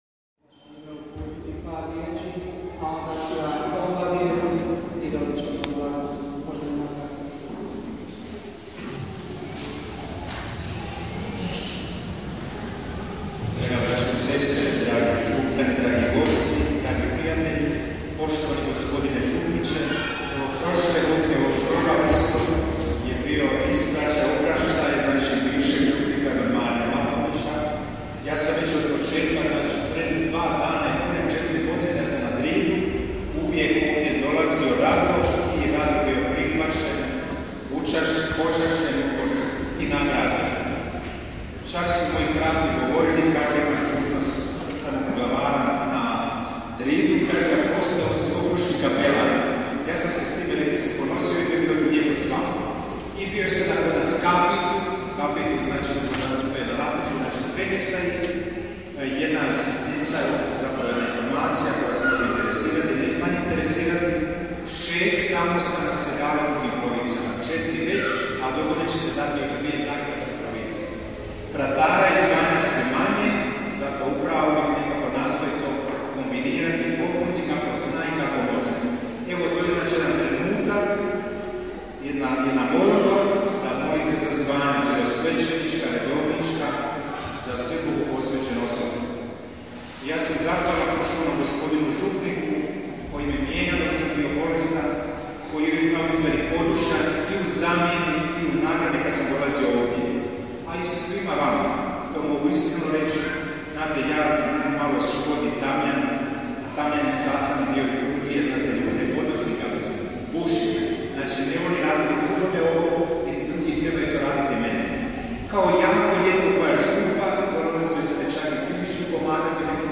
OBAVIJESTI I BLAGOSLOV – 20. NEDJELJA KROZ GODINU C